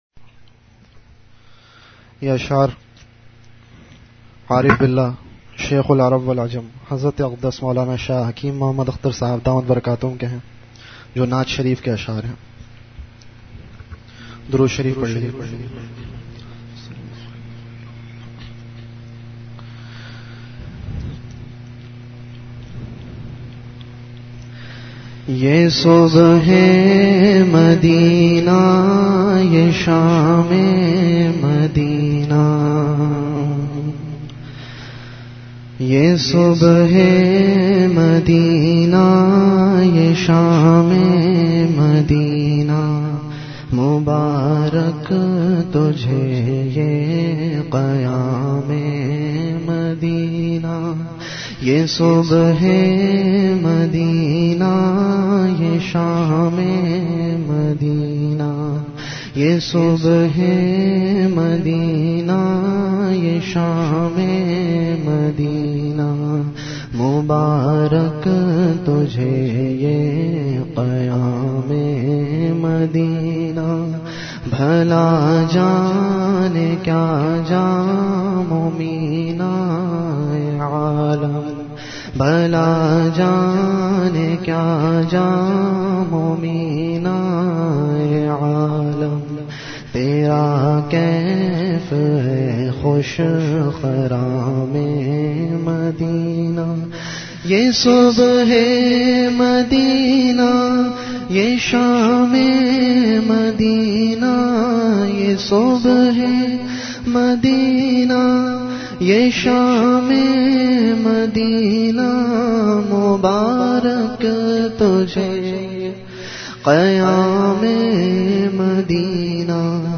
Majlis-e-Zikr
Venue Home Event / Time After Isha Prayer